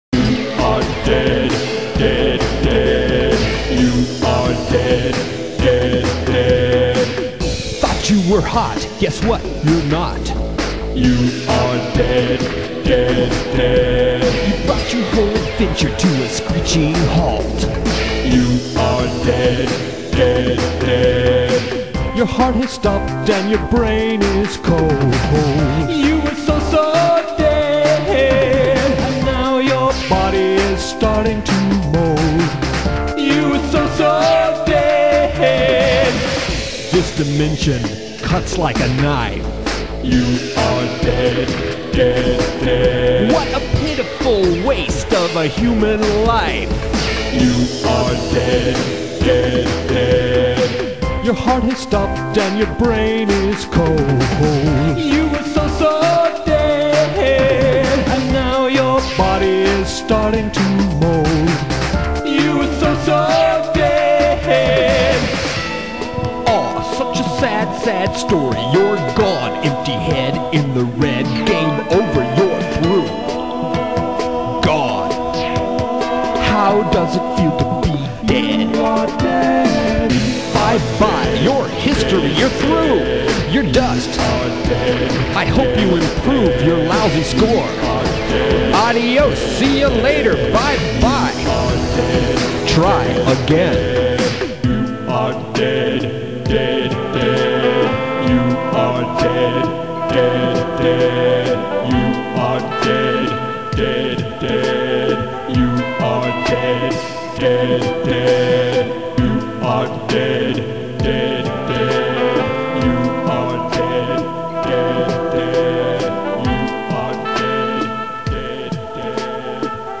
Effed Up Music